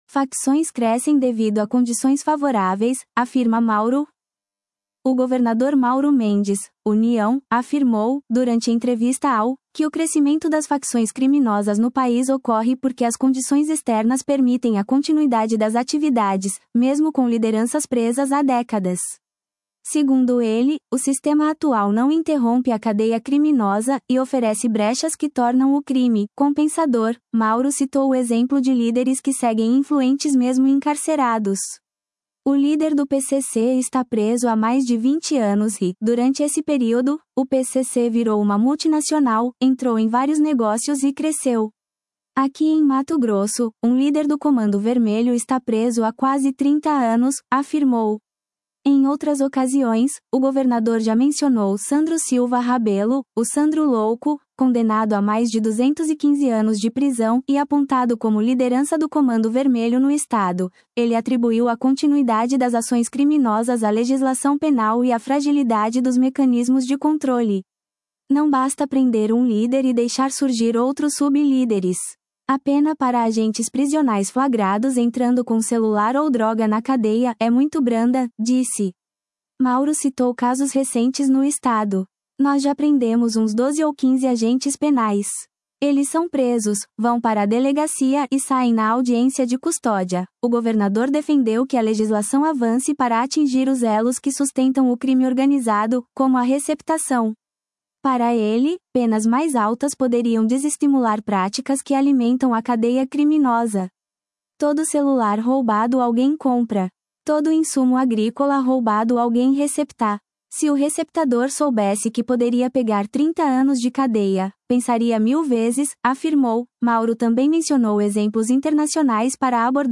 O governador Mauro Mendes (União) afirmou, durante entrevista ao , que o crescimento das facções criminosas no país ocorre porque as condições externas permitem a continuidade das atividades, mesmo com lideranças presas há décadas.